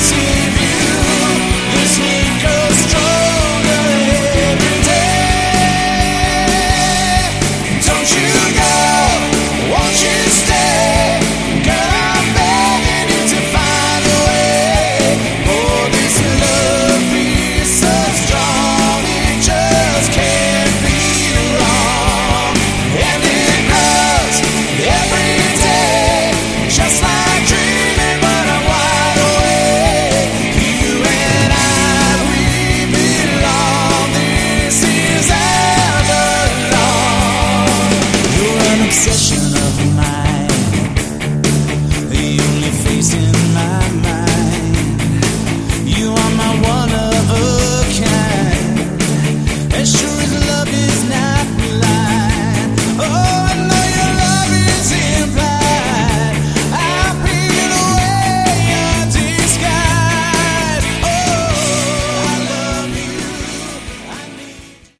Category: Melodic Hard Rock